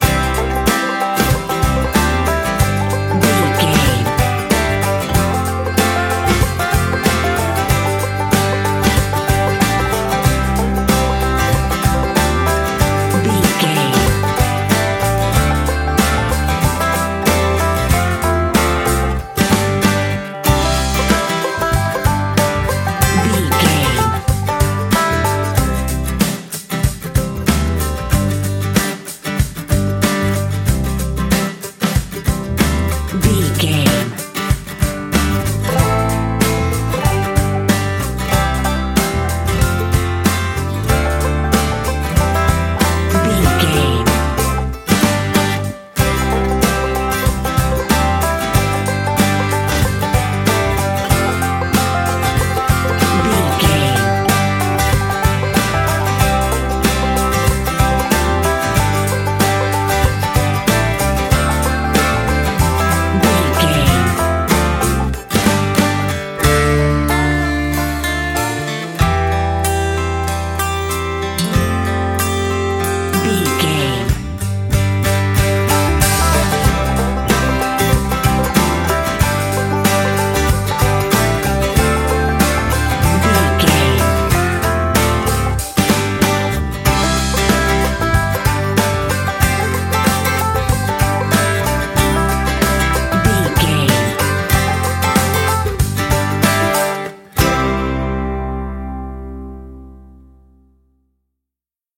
Ionian/Major
acoustic guitar
banjo
bass guitar
drums